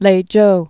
(lājō)